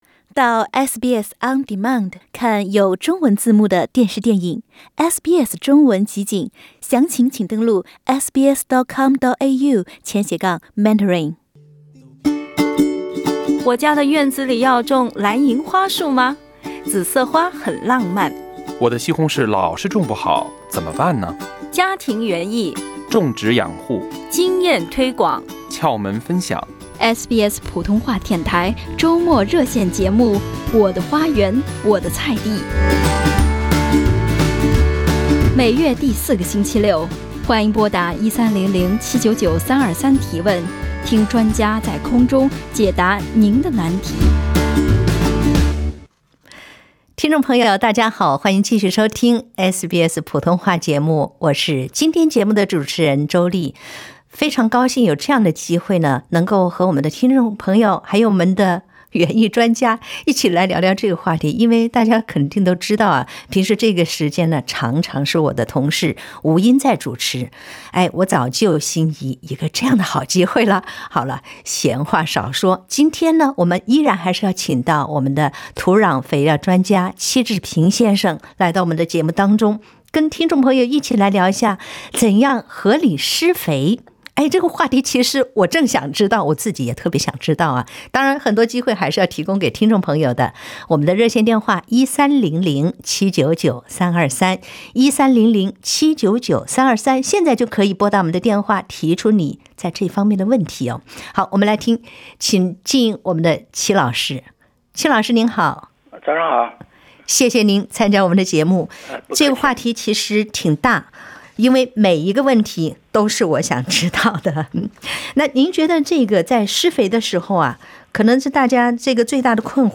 本期《我的花园，我的菜地》热线节目，电台听友分享的问题也许你也会遇到。